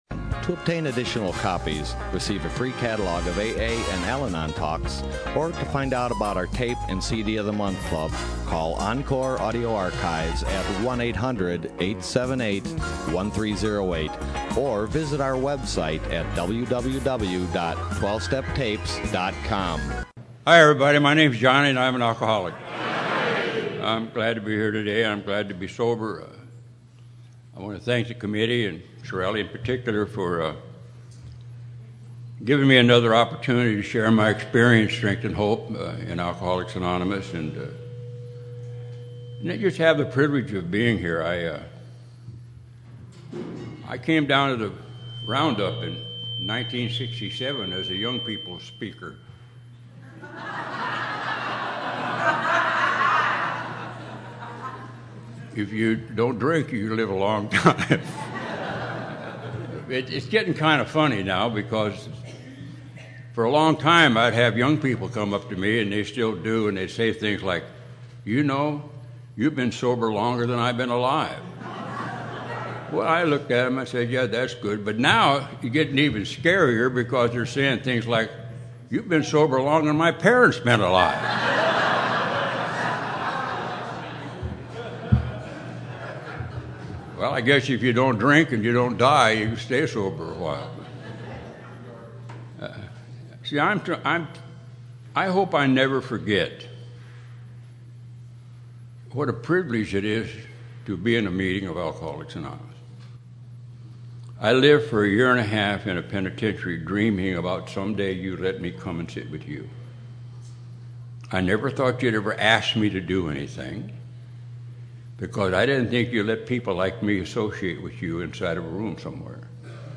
Desert POW WOW 2015